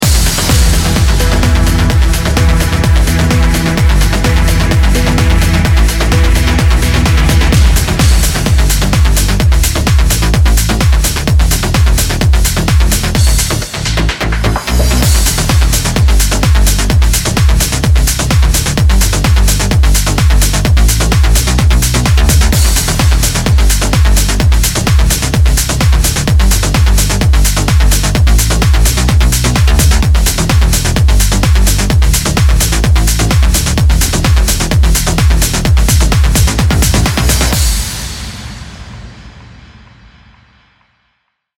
从舞蹈音乐最着名的先驱之一的声音银行中获取丰富的渐进式泛音和数十种刺耳的旋律结构，丰富自己。
炸弹种植